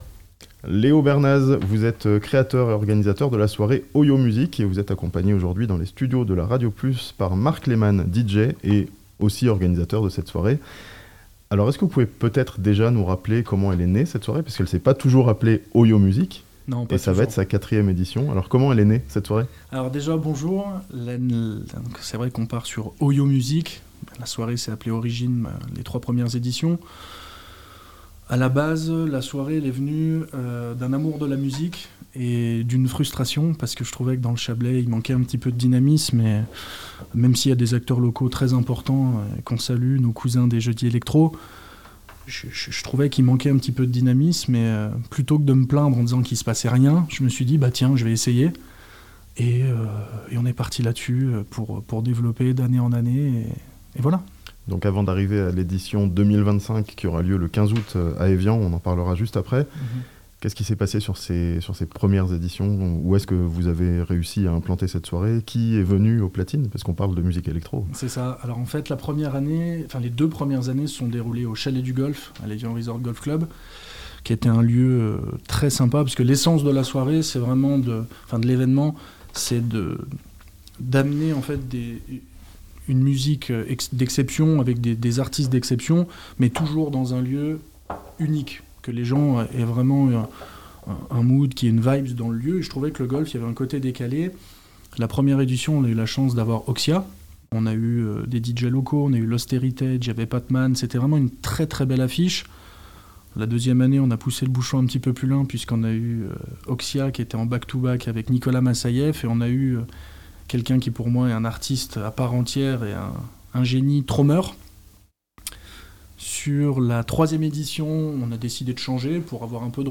OIO Music, une soirée electro le 15 août à Evian, avec Dubfire en tête d'affiche (interview) 03 Juillet 2025 La soirée aura lieu à la piscine d'Evian, dans le Chablais.